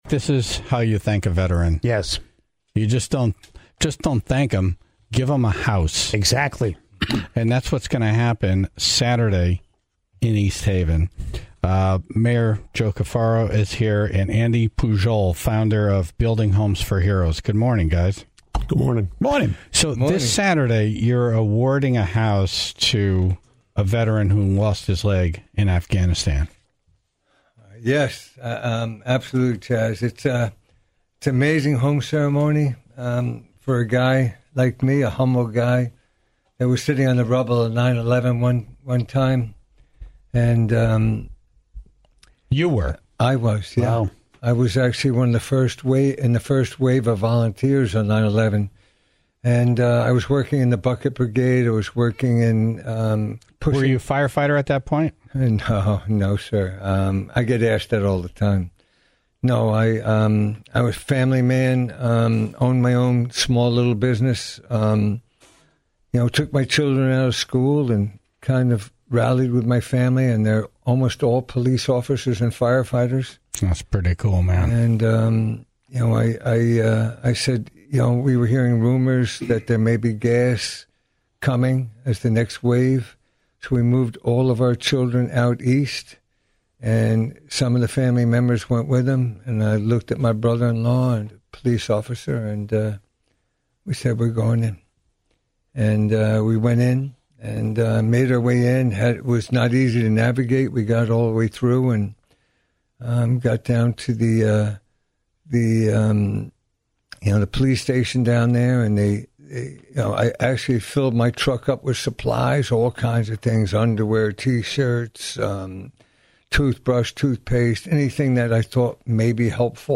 East Haven Mayor Carfora was in studio